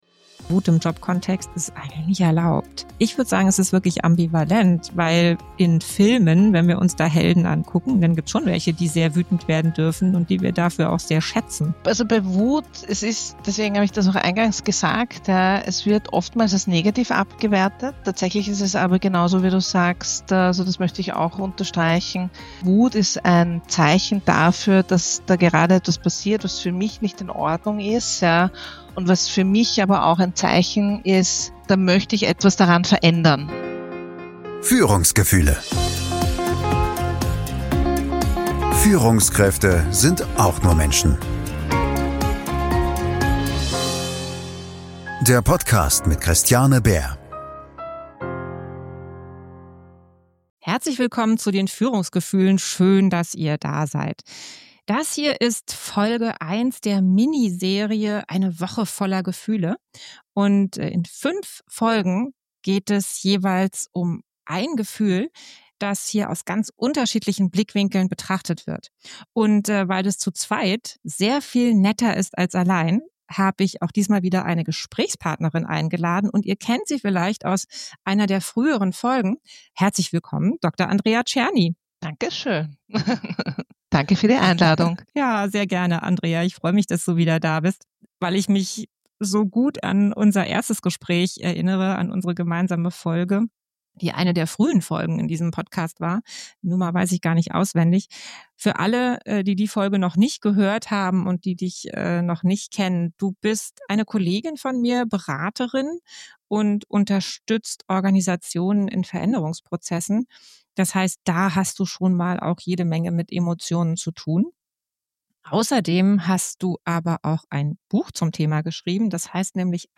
Ein Gespräch, das vieles nochmal klarer macht: Wut ist unbequem.